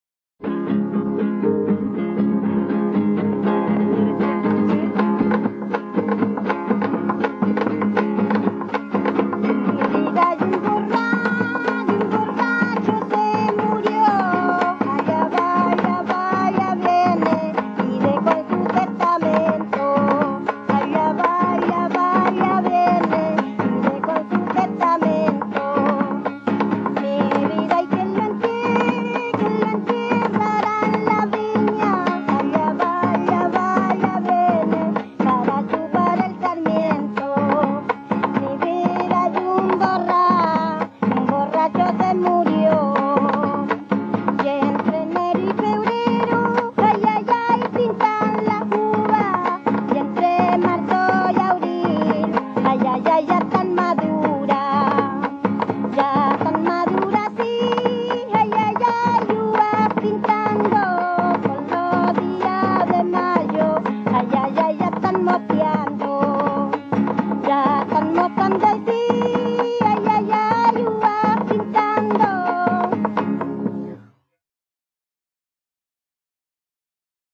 acompañada por guitarra afinada con la tercera alta y cajón.
Música tradicional
Folklore
Cueca